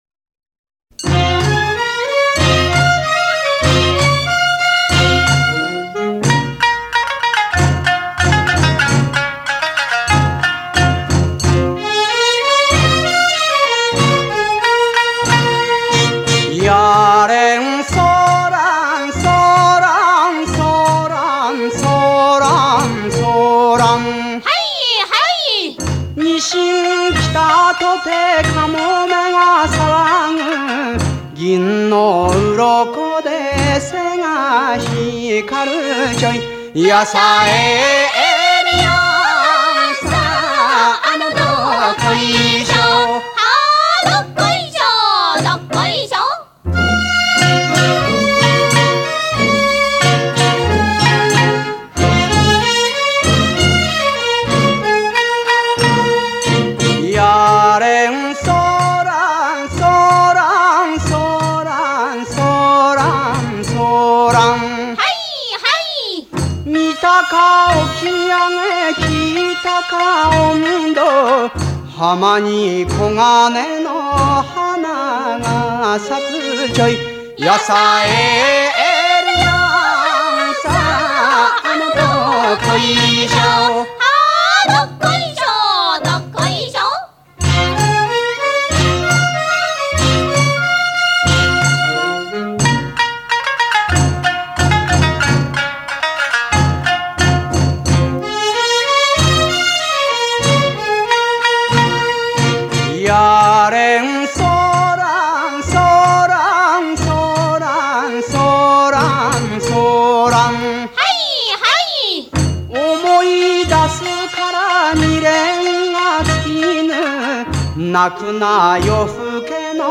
回复: [28/11/2011]求日语原声的《拉网小调》（男声主唱 女声伴唱）
日本演歌界艺人